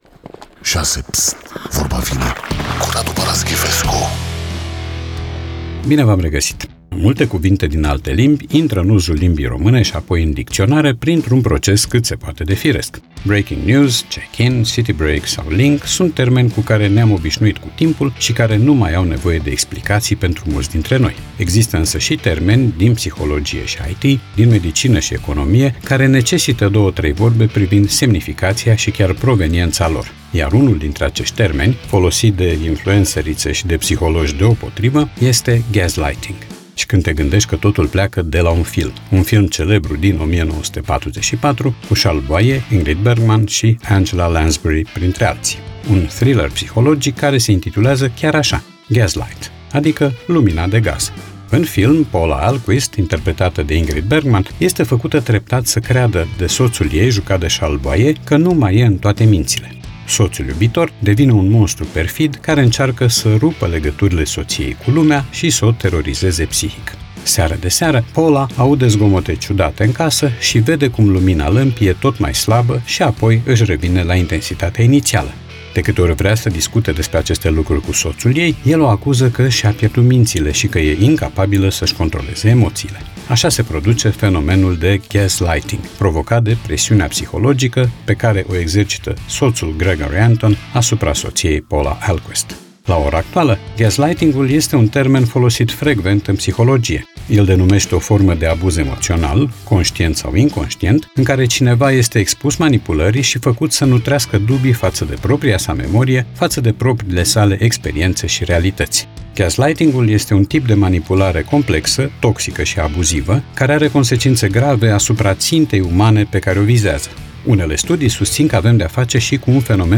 Radu Paraschivescu iti prezinta "Vorba vine", la Rock FM.